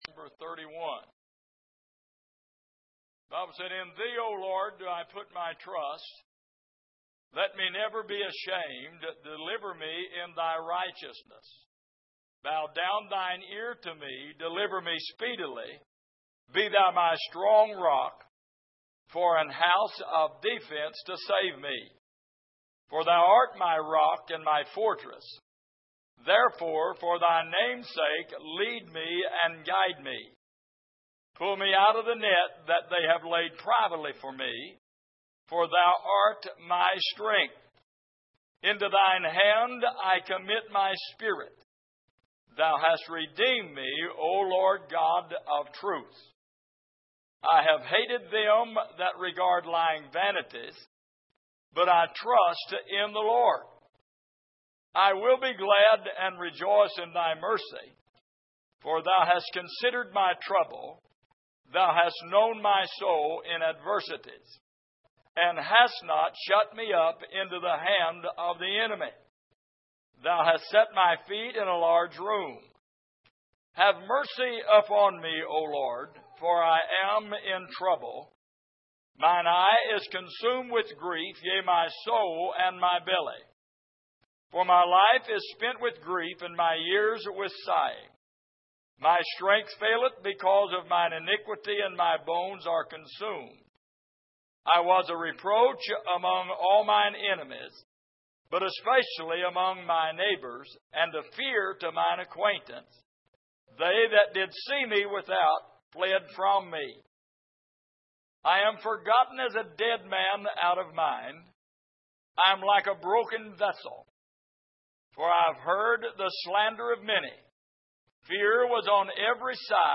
Passage: Psalm 31:1-24 Service: Midweek